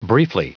Prononciation du mot briefly en anglais (fichier audio)
briefly.wav